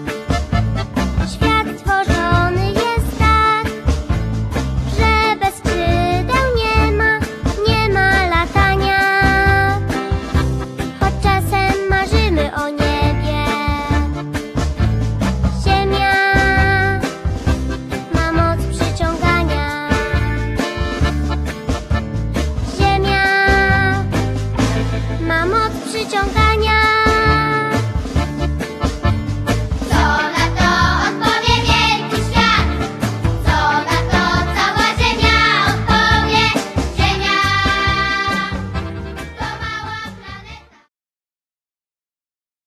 dwudziestoosobowe, muzykujące, szalone przedszkole!
perkusja
akordeon
kobza ośmiostrunowa
dzieciaki - śpiew